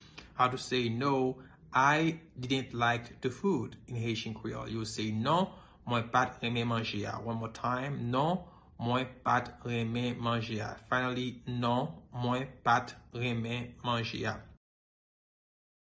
Pronunciation:
No-I-didnt-like-the-food-in-Haitian-Creole-Non-mwen-pa-t-renmen-manje-a-pronunciation.mp3